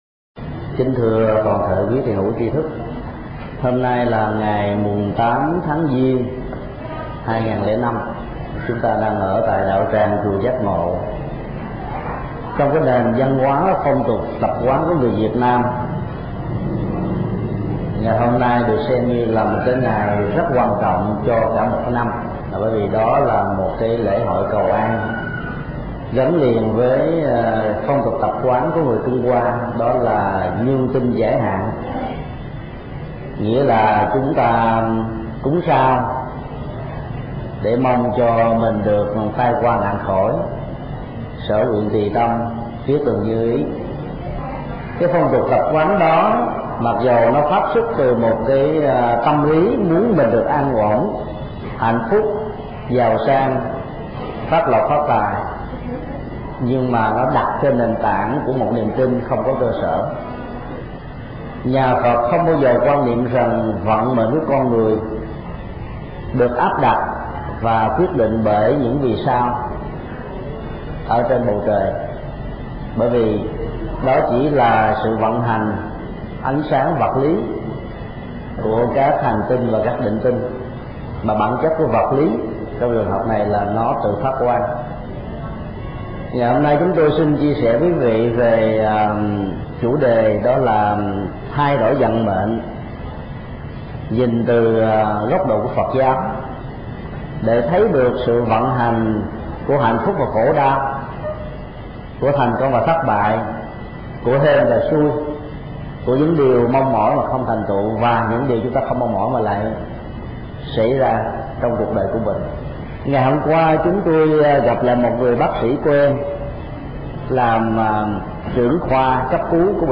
Thay đổi vận mệnh 3 – Mp3 Thầy Thích Nhật Từ Thuyết Giảng
Nghe mp3 Thuyết Giảng Thay đổi vận mệnh 3 – Thầy Thích Nhật Từ Chùa Giác Ngộ, ngày 8 tháng 1 năm 2005